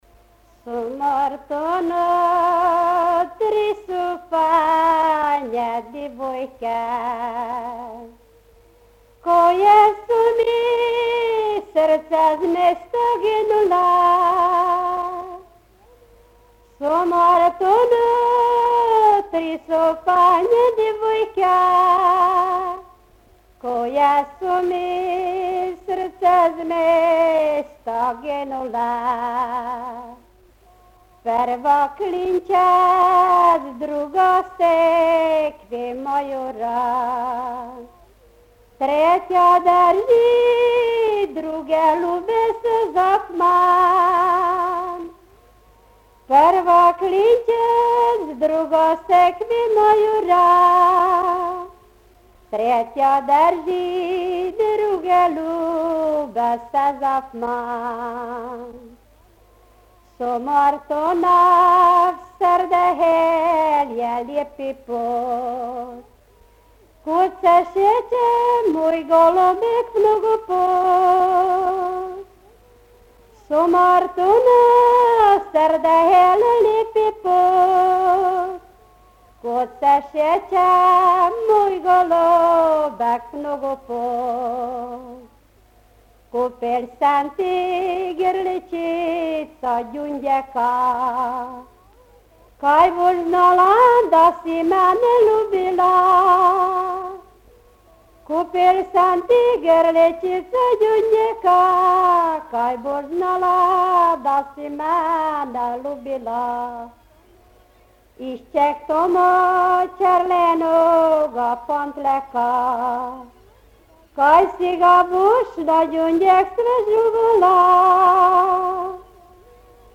Dialect: B
Locality: Tótszentmárton/Sumarton